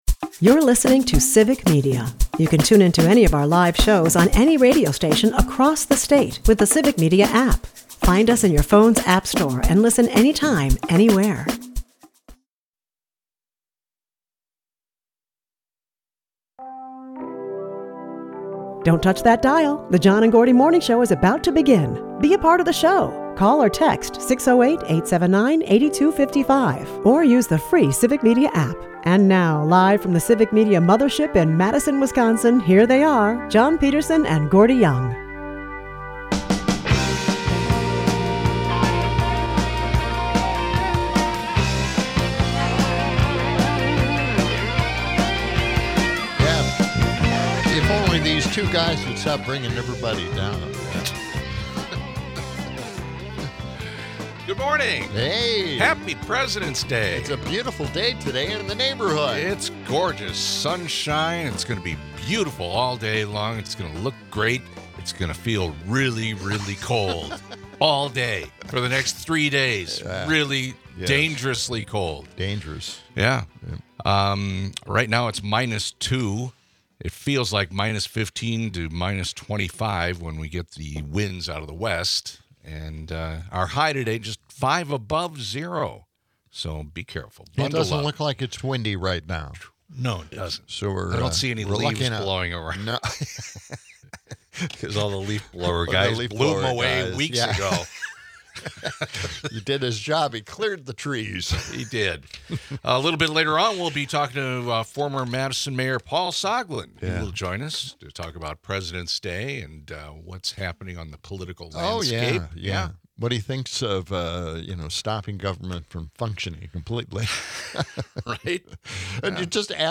We chat about background checks (and the lack thereof) for DOGE with a clip from Rep. Suozzi.
We return to the topic of "un-firing" critical employees, including nuclear safety engineers, after being cut by Musk, but are cut short by Paul Soglin, former Madison Mayor, who is on the phone for his monthly appearance. Despite our hopes of an uplifting message from the Mayor, he's got bad news for us: We've got the early stages of a coup on our hands.